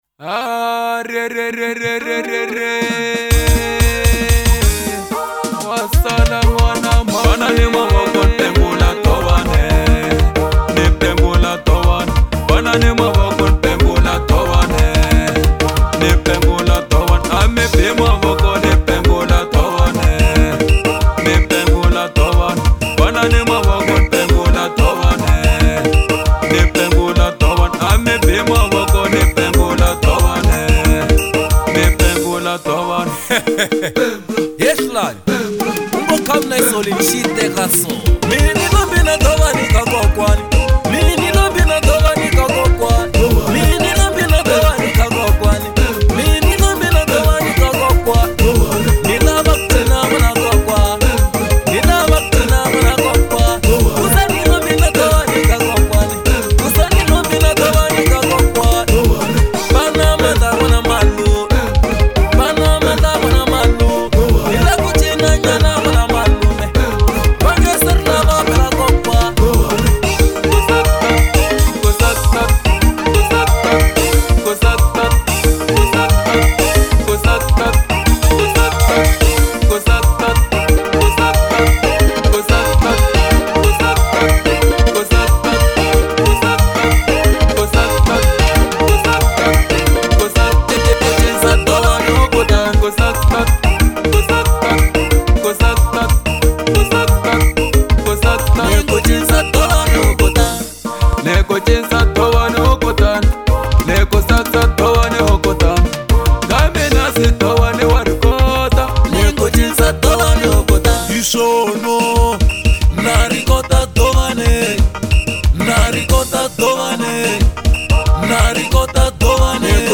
05:32 Genre : Xitsonga Size